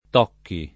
Tocchi [ t 0 kki ]